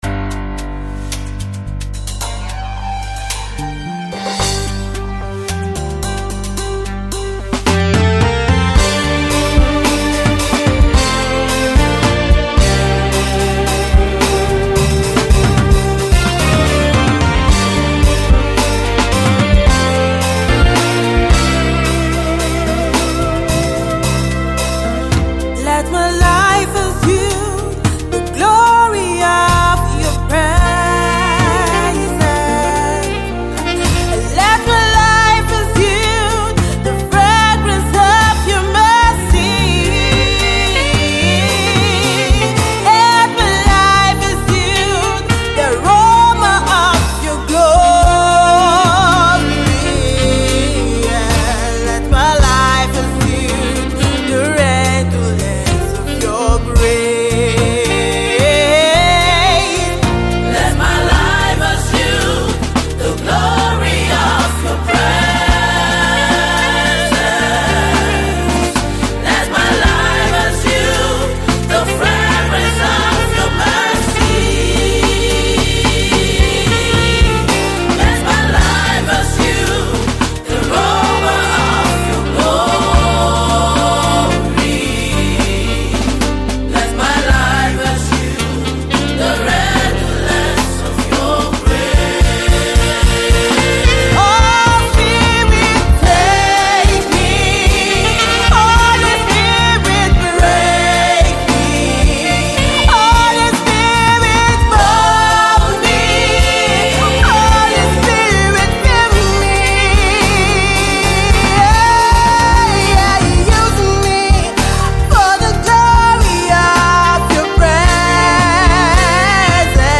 Anointed Gospel music minister
a song of prayer